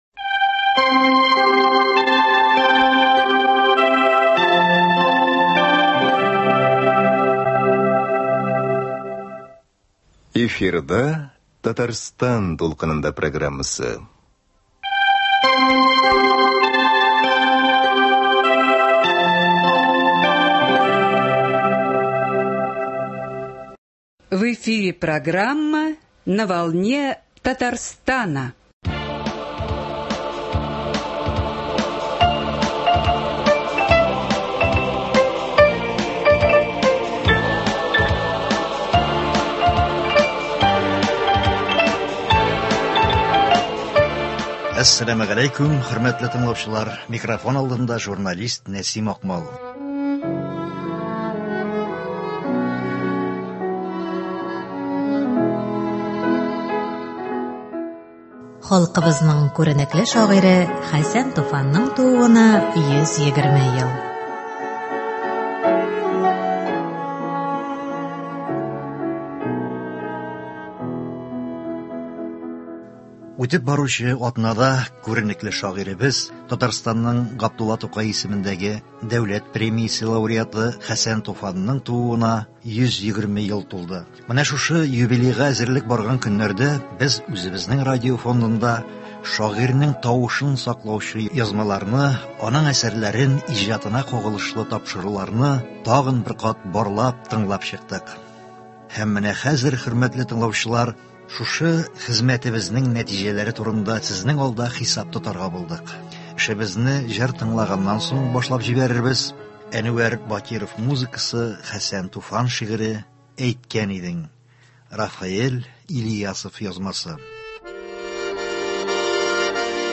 Узып баручы атнада күренекле шагыйребез, Татарстанның Габдулла Тукай исемендәге дәүләт премиясе лауреаты Хәсән Туфанның тууына 120 ел тулды. Менә шушы юбилейга әзерлек барган көннәрдә без үзебезнең радио фондында шагыйрьнең тавышын саклаучы язмаларны, аның әсәрләрен, иҗатына кагылышлы тапшыруларны тагын бер кат барлап, тыңлап чыктык. Һәм менә хәзер, хөрмәтле тыңлаучылар, шушы тикшеренүнең нәтиҗәләре турында сезнең алда хисап тотарга булдык.